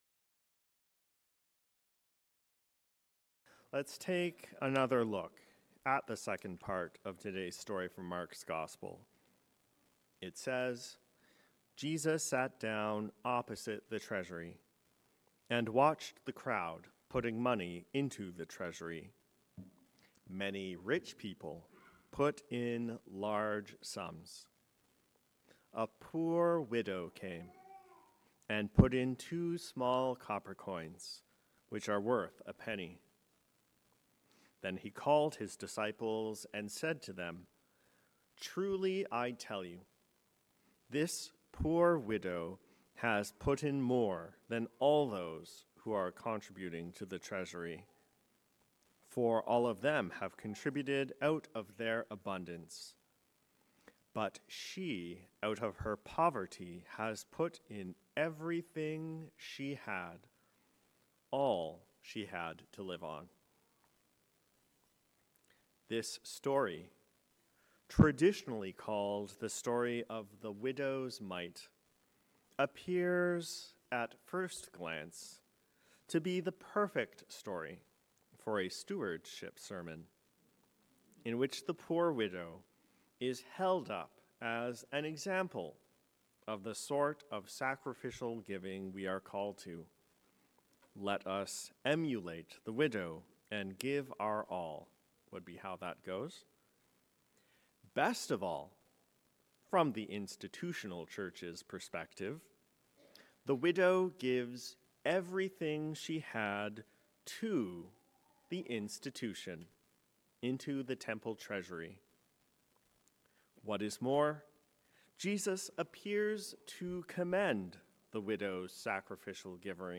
A sermon on Mark 12:38-44 – St. Helen's Anglican Church
A sermon on Mark 12:38-44